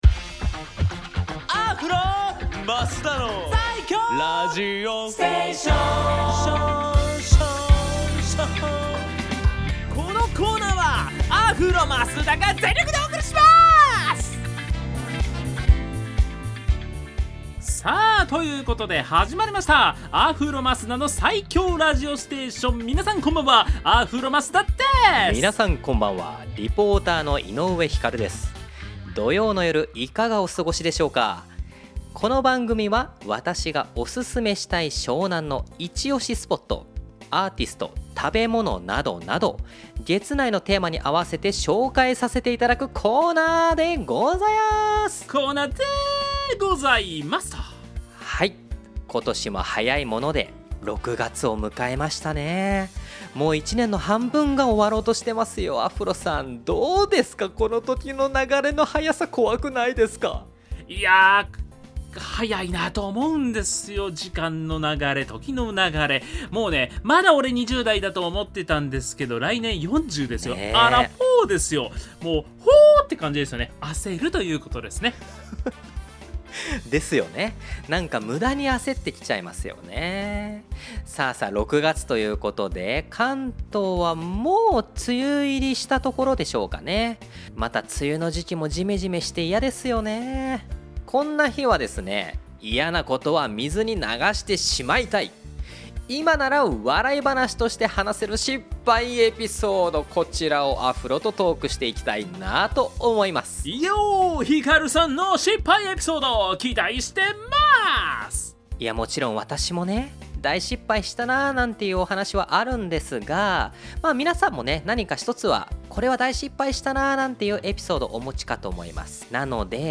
放送音源はこちら